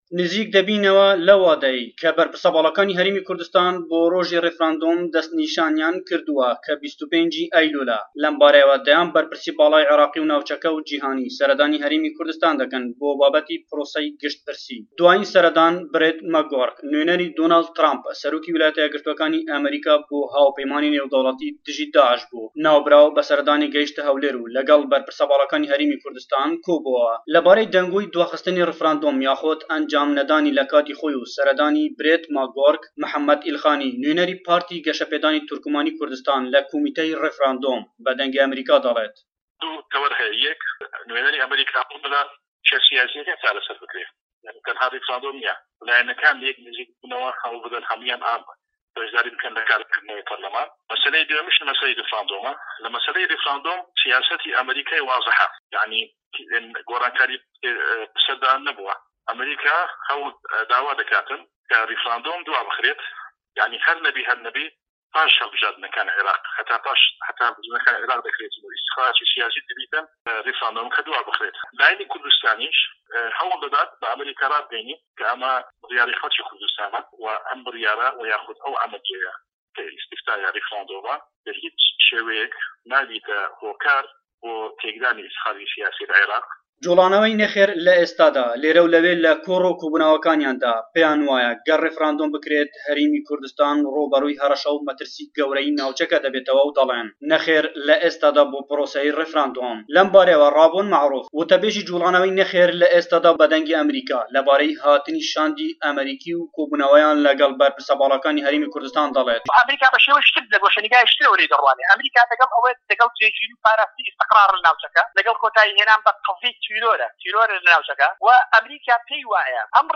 Raport - Hewlêr